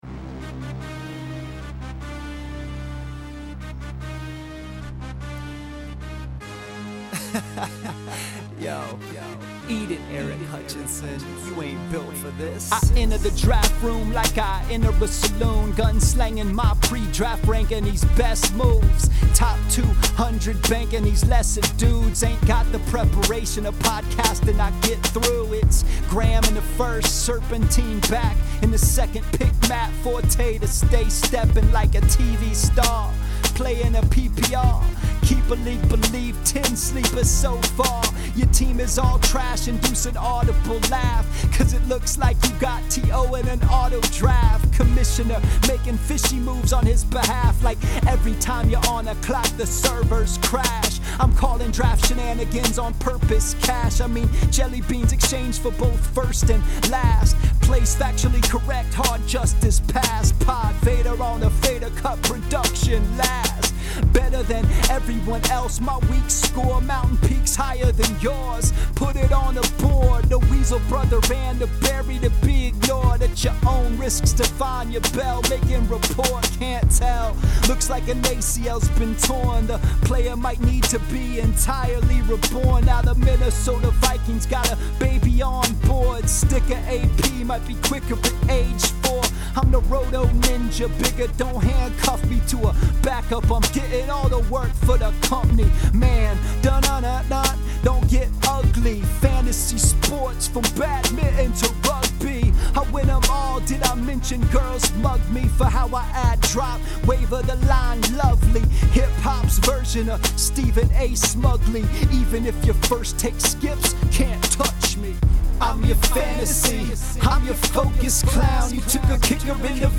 This is hip hop.